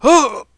vs_fScarabx_hit2.wav